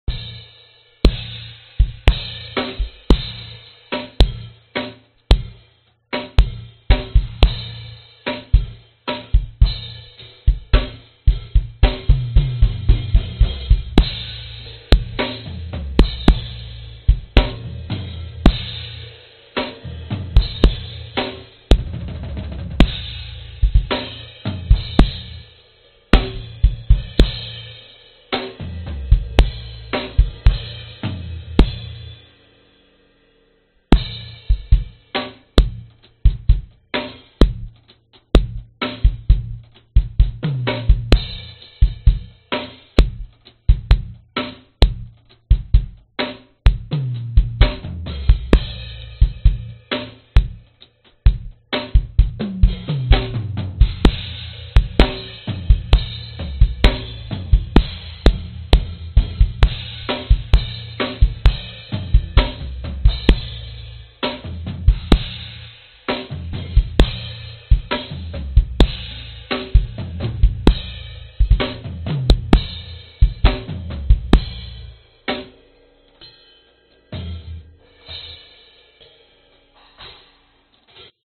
标签： drums percussion tamborine instrumental rock
声道立体声